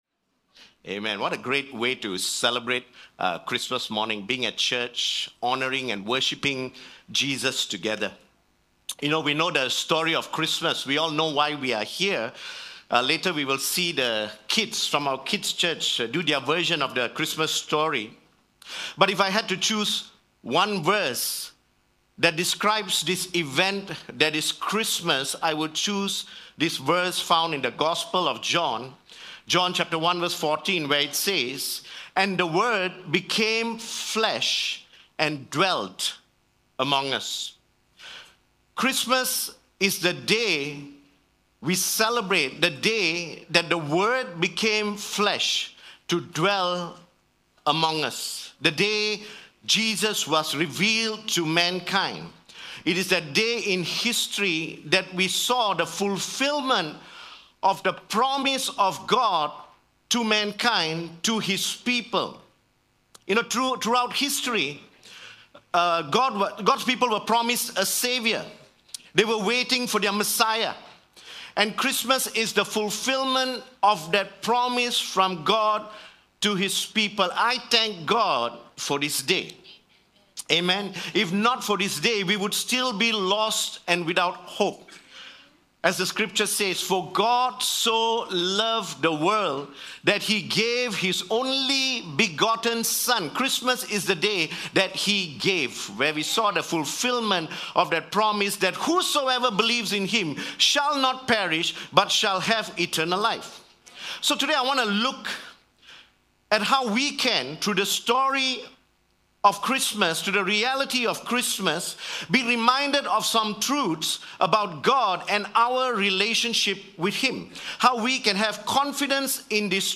Sermons – DestinyC3